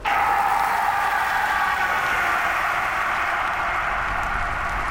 Low Pitched Scream Sound Button - Free Download & Play
Reactions Soundboard1,997 views